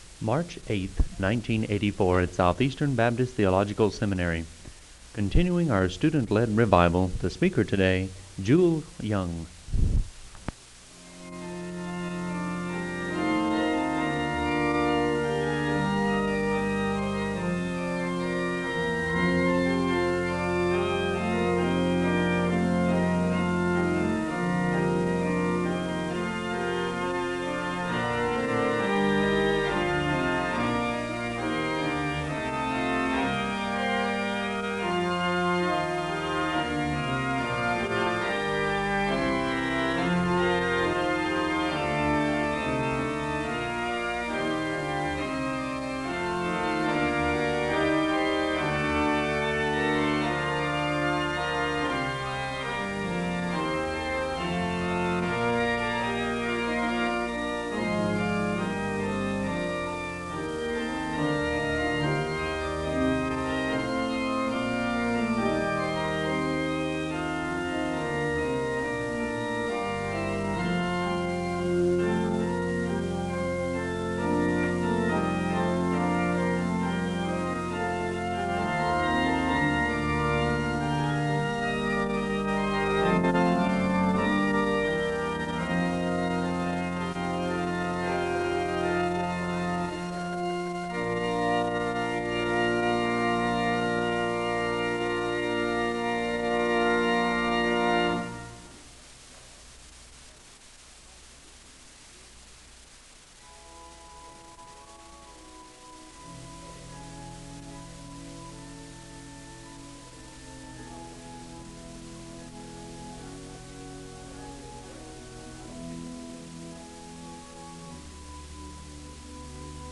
The service begins with organ music (00:00-05:21). The speaker delivers the call to worship (05:22-06:20).
A student gives his testimony (10:29-16:25). A soloist performs a song of worship (16:26-20:24).